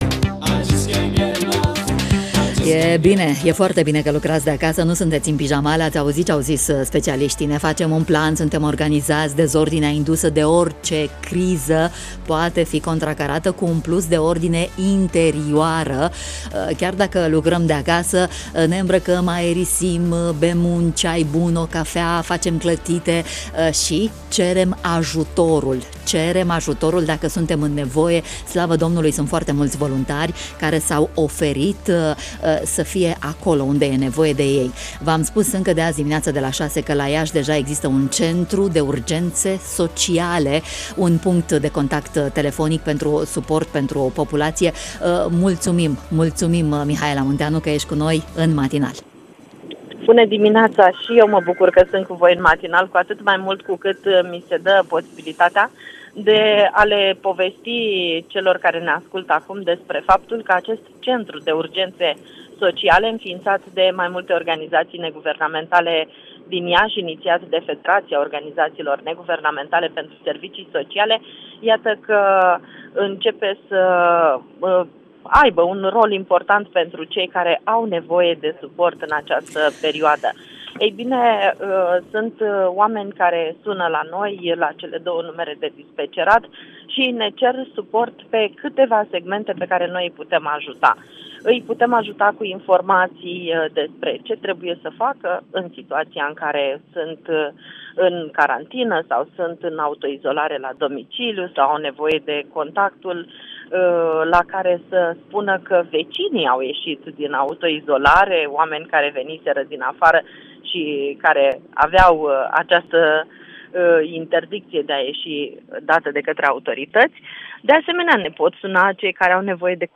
în matinalul Radio România Iaşi: